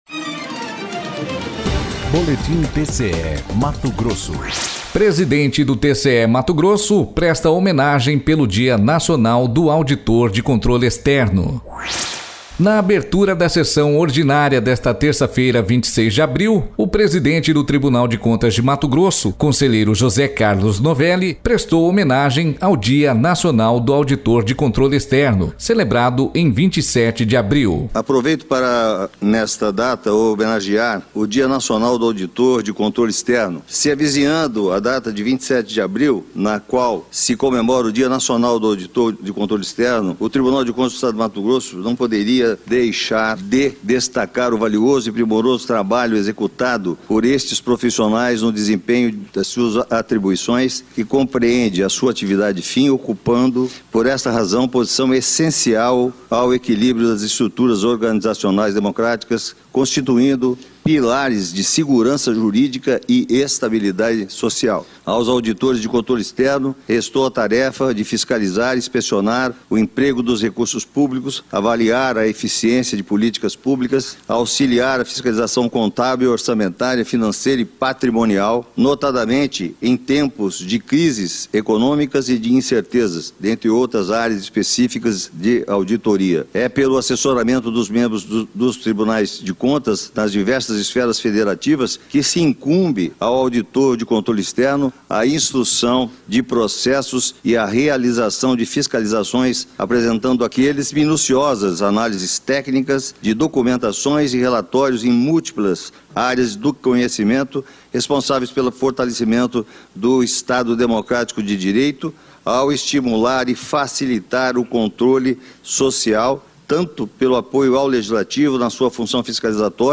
Na abertura da sessão ordinária desta terça-feira, 26 de abril, o presidente do Tribunal de Contas de Mato Grosso, conselheiro José Carlos Novelli, prestou homenagem ao Dia Nacional do Auditor de Controle Externo, celebrado em 27 de abril.// Sonora: José Carlos Novelli – conselheiro do TCE-MT